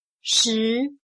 /Shí/Conocimiento; saber; conocer; reconocer